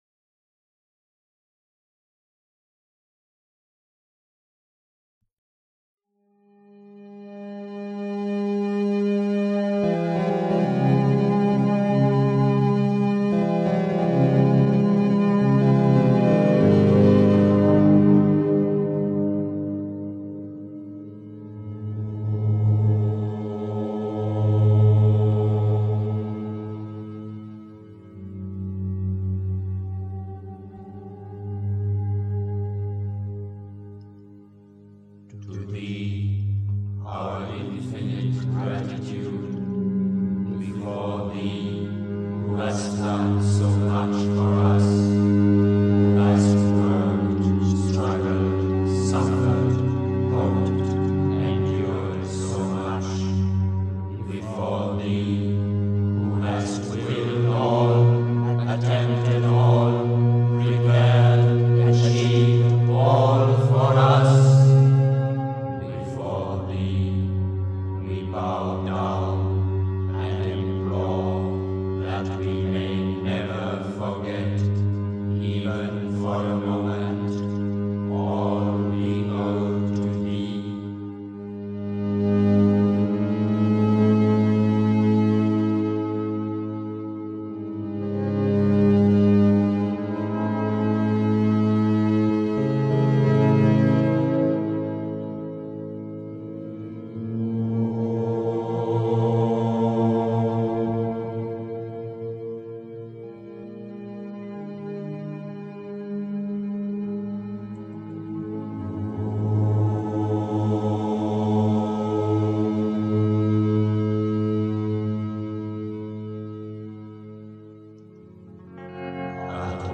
Was ich „auf dem Weg sein“ nenne (Die Mutter, Bulletin, Feb. 1975, p. 45) 3. Zwölf Minuten Stille.